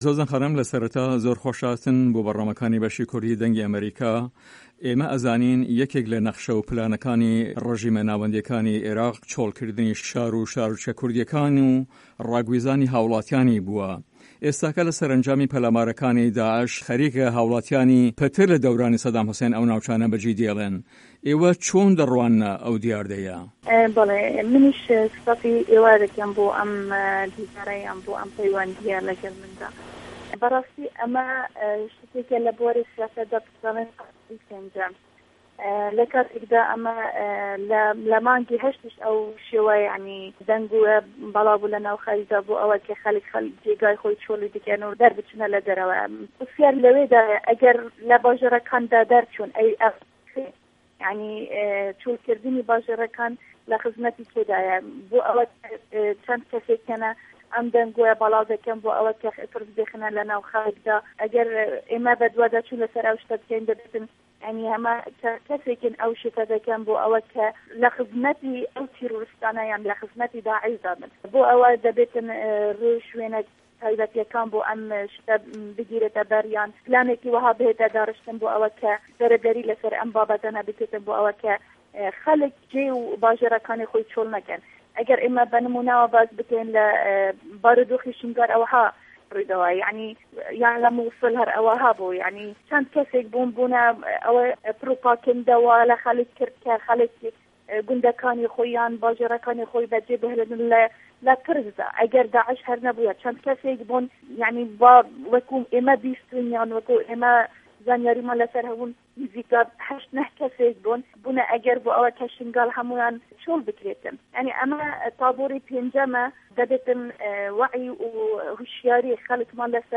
هه‌رێمه‌ کوردیـیه‌کان - گفتوگۆکان
دکتۆر زۆزان سادق سه‌عید ئه‌ندام په‌رله‌مانی هه‌رێمی کوردستان له‌ سه‌ر لیستی پارتی دیموکراتی کوردستان له‌ هه‌ڤپه‌ێڤینێکدا له‌گه‌ڵ به‌شی کوردی ده‌نگی ئه‌مه‌ریکا ده‌لێت"ئه‌بێ بپرسین ئاخۆ چۆڵکردنی شاره‌کان له‌ به‌رژه‌وه‌ندی کی دایه‌، زۆزان خانم ده‌ڵی چه‌ند که‌سانێک هه‌یه‌ ئه‌م ده‌نگۆیه‌ بڵا ده‌که‌نه‌وه‌ بۆ ئه‌وه‌ی ترس بخه‌نه‌ ناو دڵی خه‌ڵک و ئه‌مه‌ش له‌ به‌رژه‌وه‌ندی تیرۆریستان دایه‌ و هه‌ر له‌به‌ر ئه‌وه‌ ده‌بێت رێ و شوێنه‌ تاێبه‌تیه‌کان بگردرێته‌ به‌ر وه‌ یاخود پلانێکی وه‌ها بێته‌ داڕشتن بۆ رێگرتن له‌و کارانه‌ و خه‌ڵک جێ و باژێره‌کانی خۆیان به‌ جێ نه‌هێڵن.